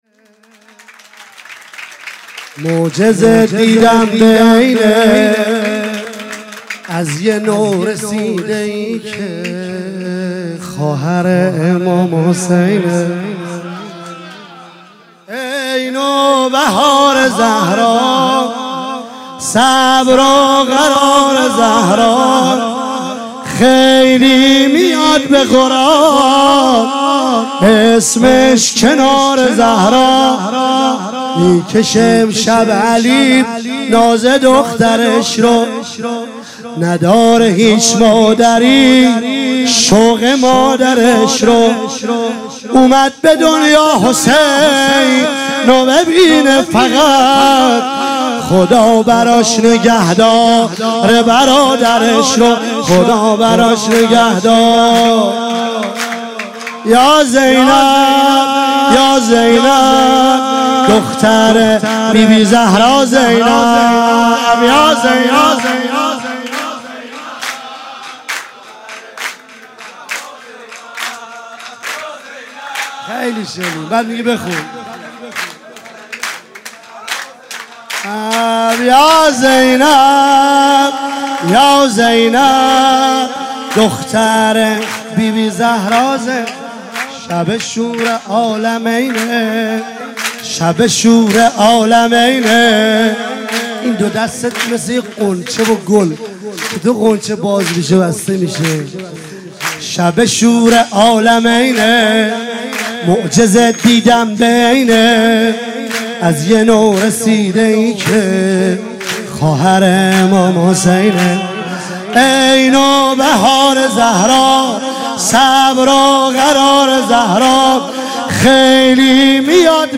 ولادت حضرت زینب سلام الله علیها